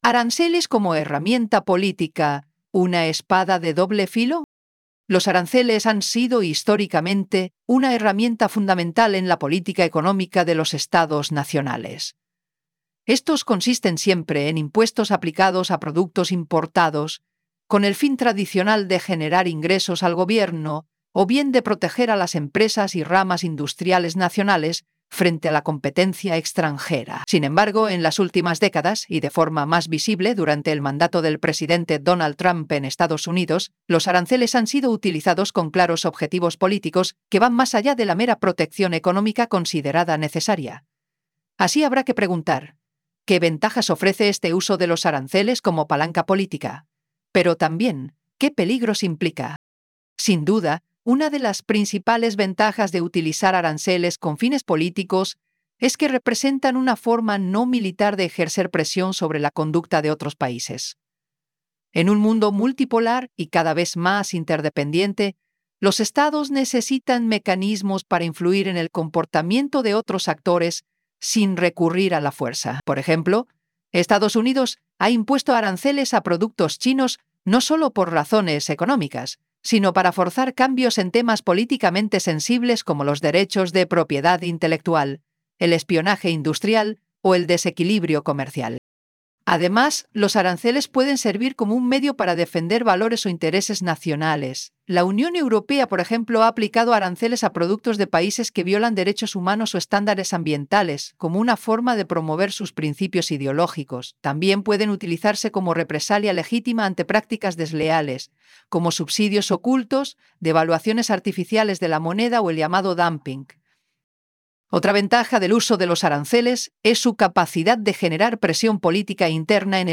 PlayAI_Aranceles_como_herramienta_poli_tica_una.wav